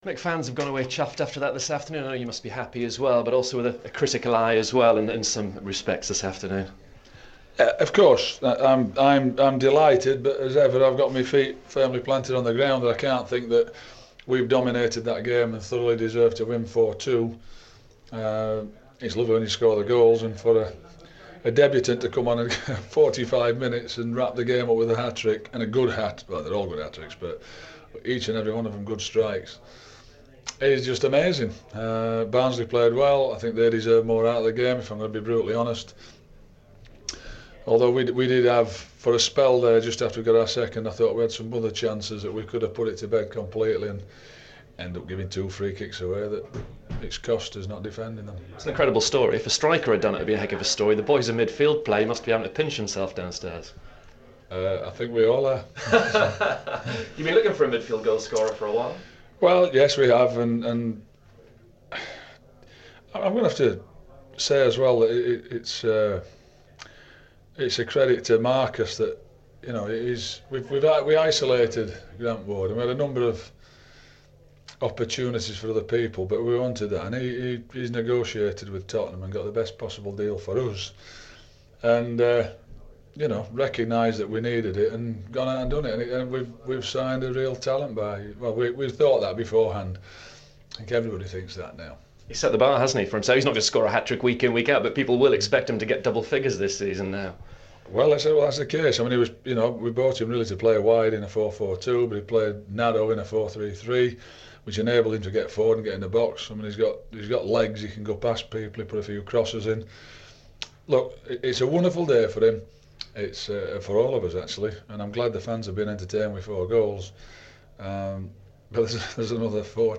Ipswich Town manager Mick McCarthy speaks to BBC Suffolk Sport after Saturday's victorious season opener.